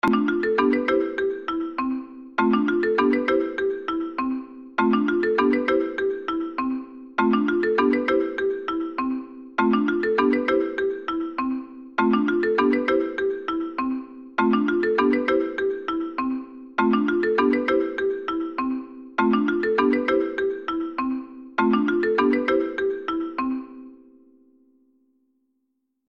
دانلود آهنگ تلفن 7 از افکت صوتی اشیاء
دانلود صدای تلفن 7 از ساعد نیوز با لینک مستقیم و کیفیت بالا
جلوه های صوتی